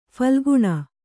♪ phalguṇa